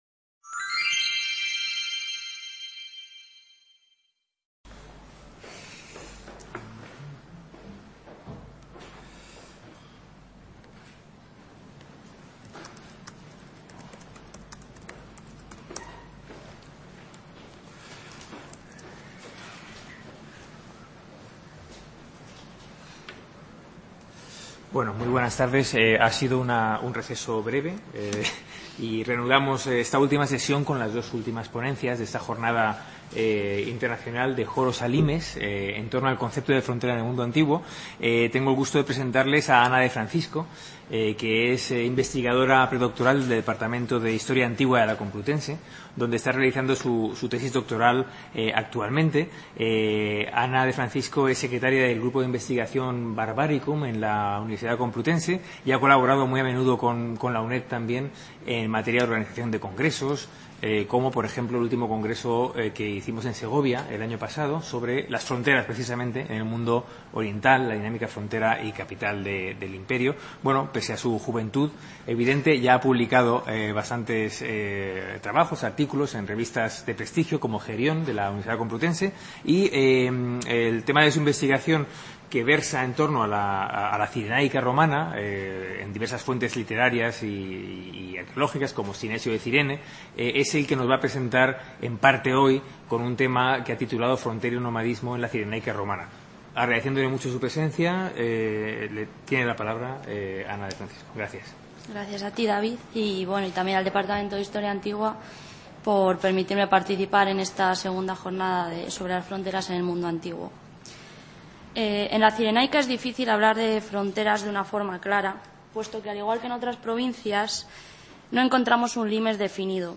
Esta II jornada de estudios sobre la frontera propone una serie de contribuciones con estudios de casos sobre el concepto de frontera en el mundo antiguo y medieval. Los conferenciantes ofrecerán una aproximación a las fuentes comparadas sobre el concepto de límite desde la época griega clásica hasta el Imperio Romano de Oriente, en los comienzos del medievo bizantino, y la Baja Edad Media.